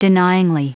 Prononciation du mot denyingly en anglais (fichier audio)
Prononciation du mot : denyingly